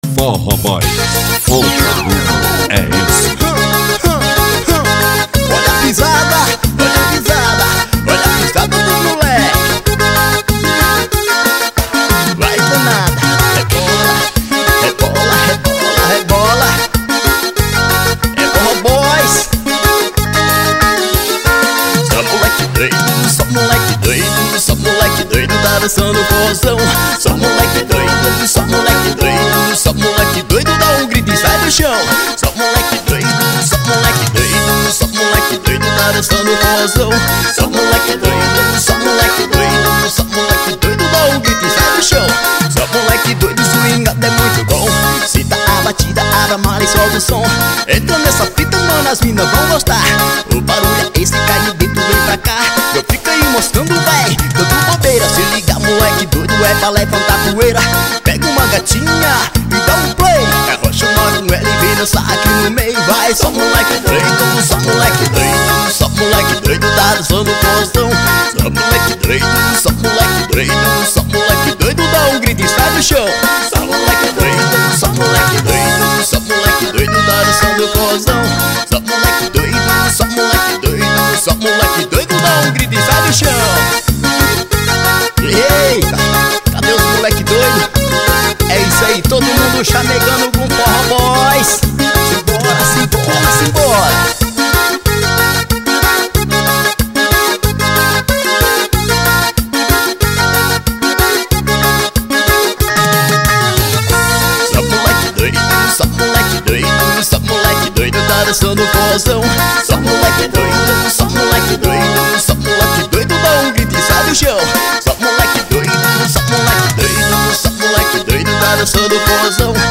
2024-05-03 08:00:01 Gênero: Forró Views